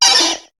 Cri de Têtarte dans Pokémon HOME.